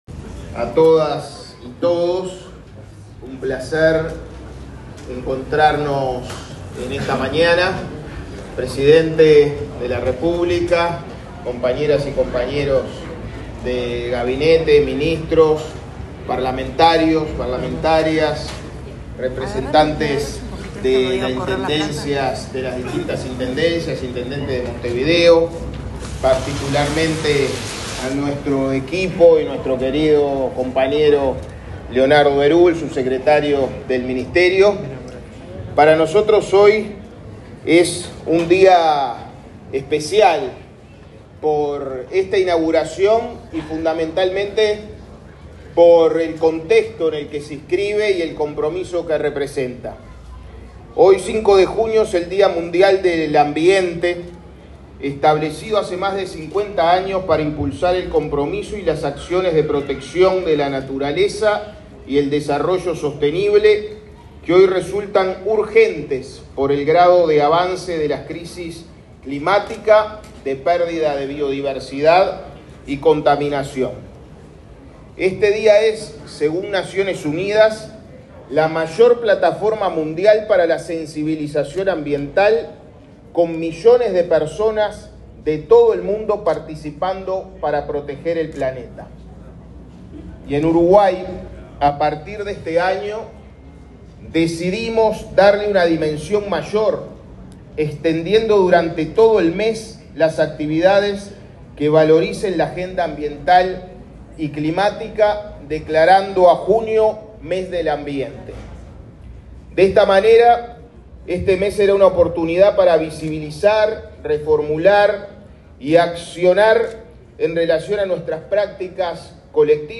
Palabras del ministro de Ambiente, Edgardo Ortuño
Palabras del ministro de Ambiente, Edgardo Ortuño 05/06/2025 Compartir Facebook X Copiar enlace WhatsApp LinkedIn En la inauguración de la 4.ª edición de la Expo Uruguay Sostenible, el ministro de Ambiente, Edgardo Ortuño, anunció medidas para el cuidado del ambiente.
ortuno_oratoria.mp3